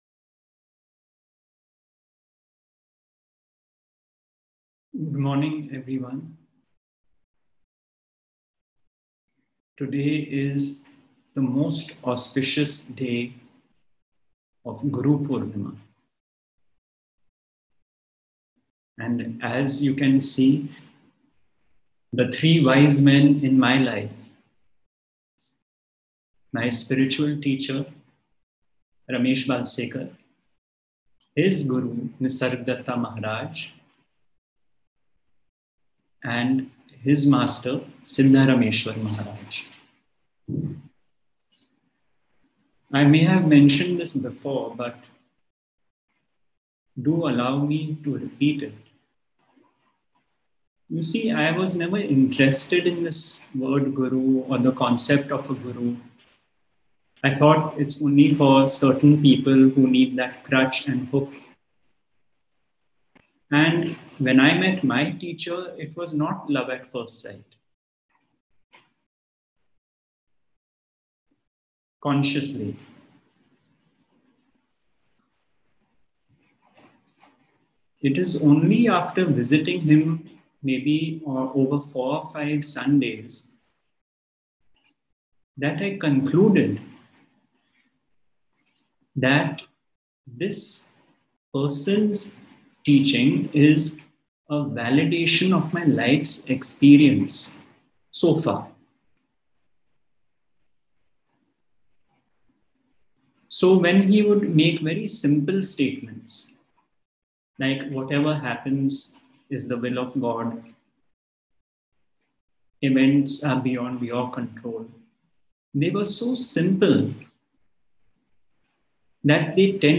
Guru Purnima Satsang — Love as the Unity of Consciousness